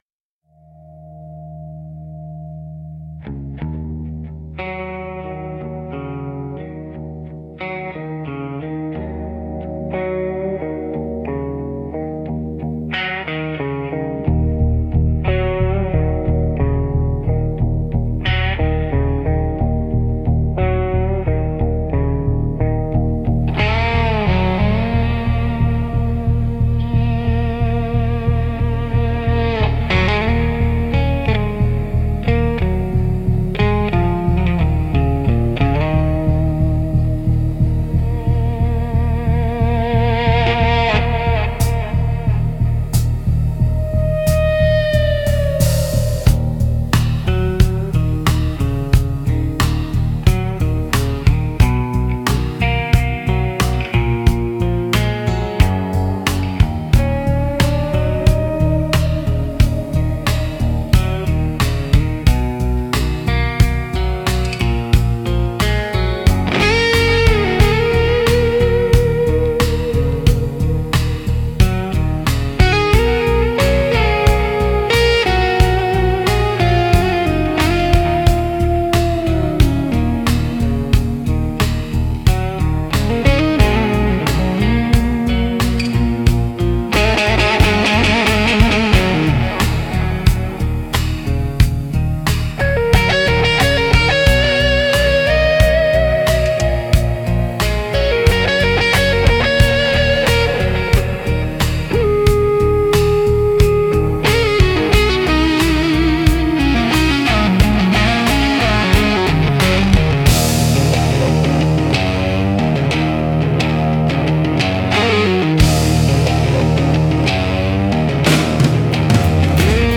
Instrumental - Beneath the Steel Sky Blues 4.14